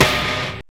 Index of /90_sSampleCDs/Roland L-CD701/SNR_Snares 2/SNR_Sn Modules 2
SNR LO-FI 08.wav